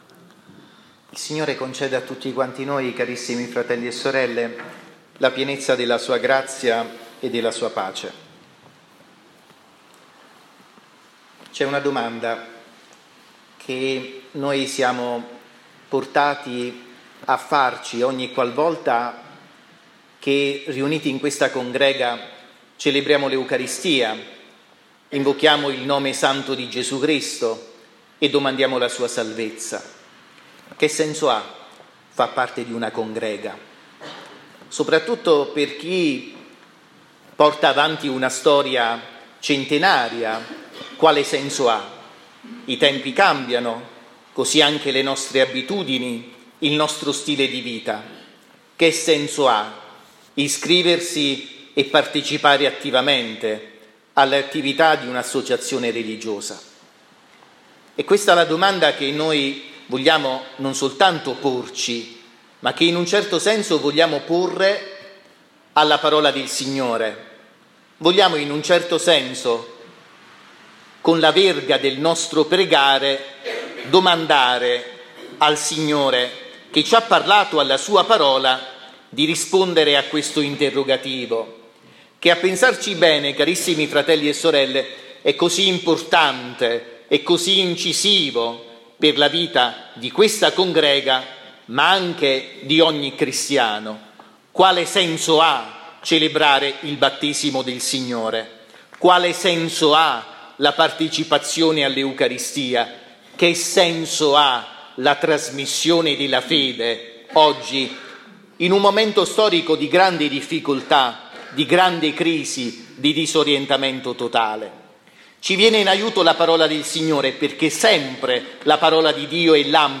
omelia-7-gennaio.mp3